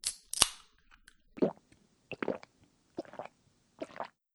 Open can and drink.wav